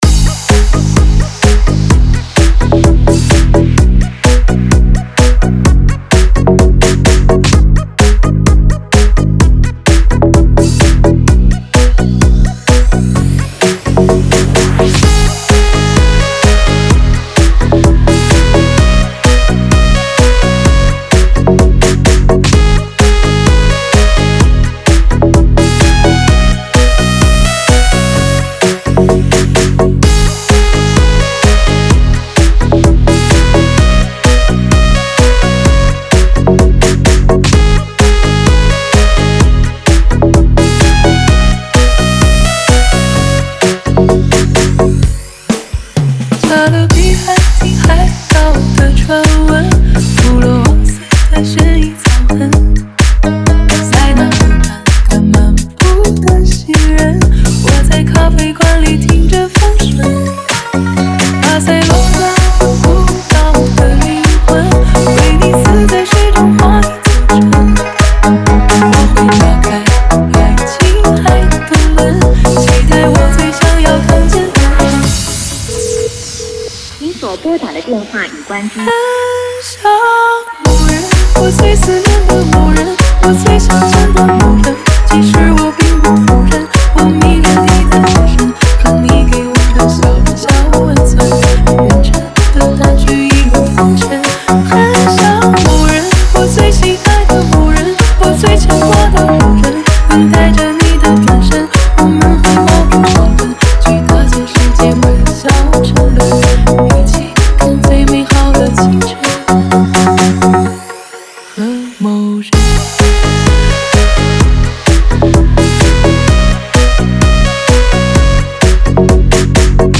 4天前 DJ音乐工程 · FunkyHouse 3 推广